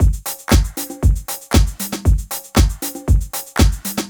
04 Drumloop.wav